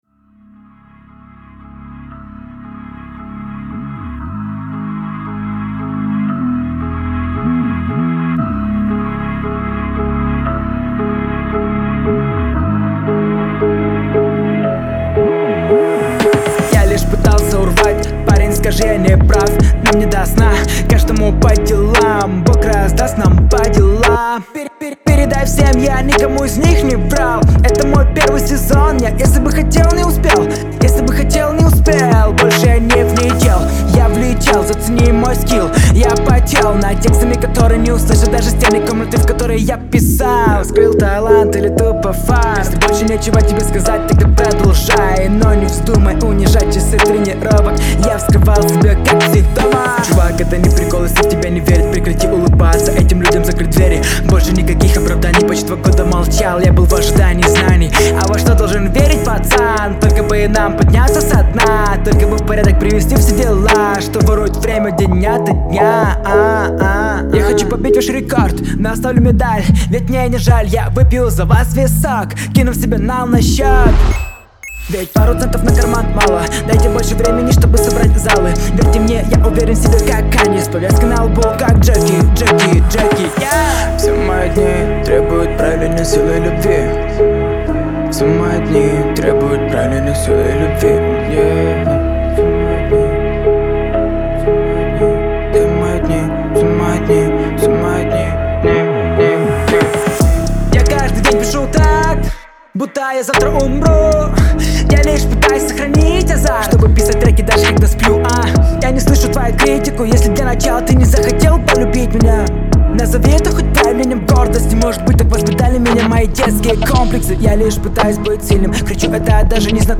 C#m  C#m  G#m  E
327 просмотров 795 прослушиваний 35 скачиваний BPM: 115